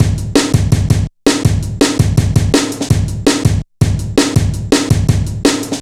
Index of /90_sSampleCDs/Zero-G - Total Drum Bass/Drumloops - 3/track 45 (165bpm)